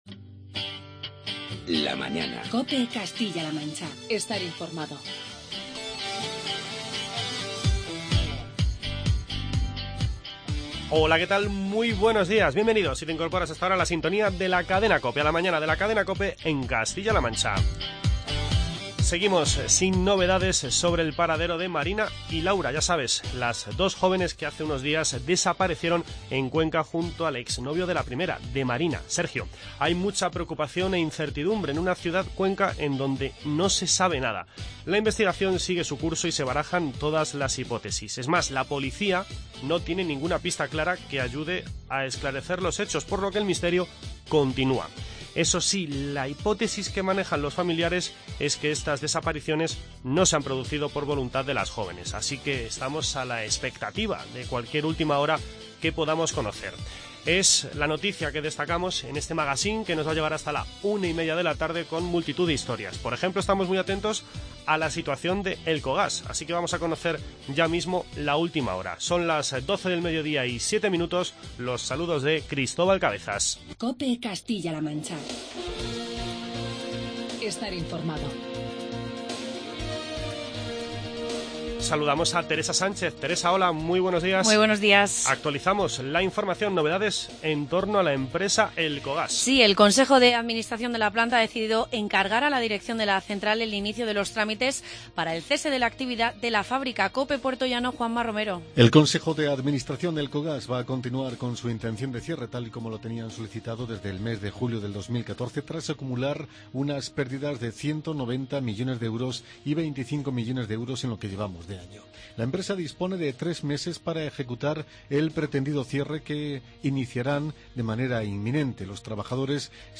Charlamos con Jaime Carnicero, vicealcalde de Guadalajara, y con Teo Ibáñez, alcalde de Valeria